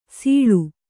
♪ sīḷu